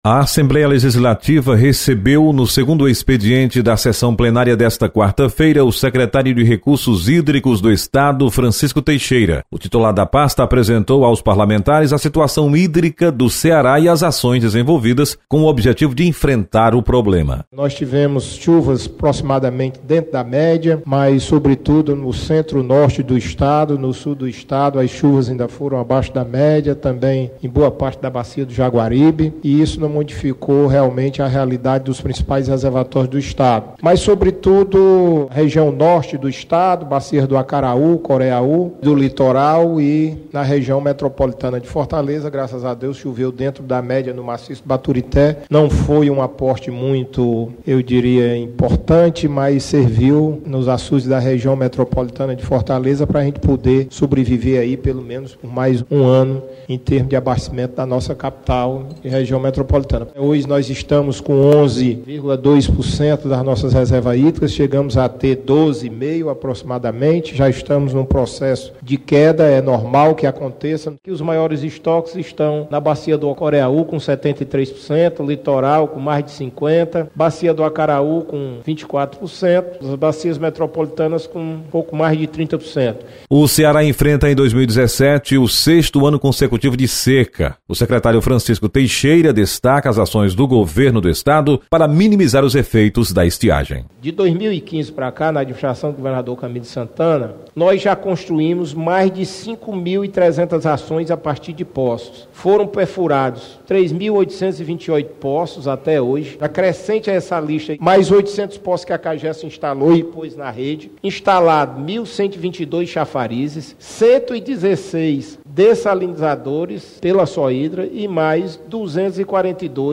Secretário de Recursos Hídricos do Estado,Francisco Teixeira destaca ações para o setor.